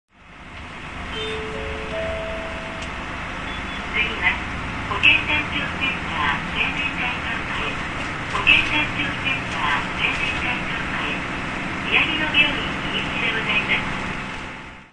♪Play] 車　　輛 : [東]日産ディーゼル U-UA440LAN 62-55